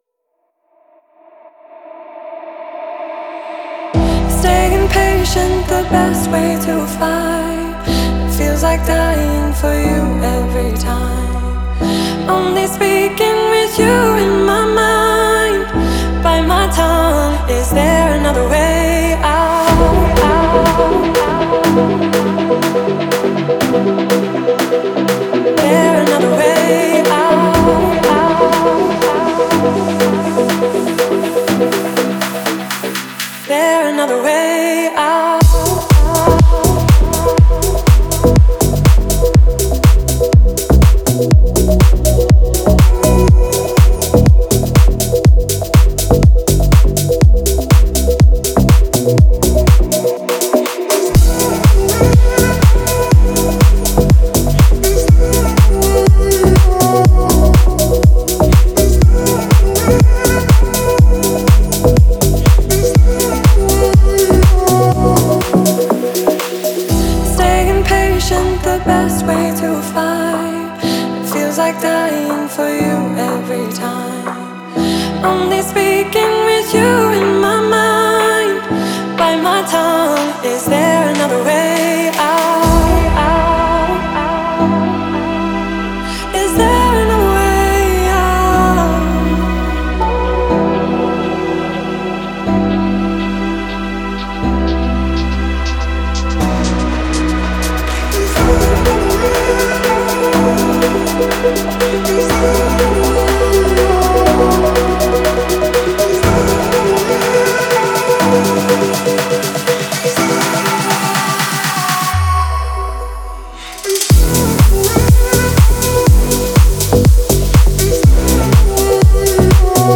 это динамичная композиция в жанре EDM